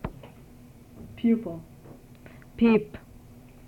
These sound files were collected from a larger group of files located Archive Databases from the Phonetics Resources at UCLA.
Sound file #1 - File below demonstrates the word, "pupil" in the both the English and Armenian language. The interest lies in the "i" and its sound. pʰipʰ is the transcription of the word collected from list of data from the first sound file.
This visual is an analysis of word, "Pupil" spoken in both English and Armenian.